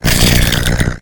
Cri de Charbi dans Pokémon HOME.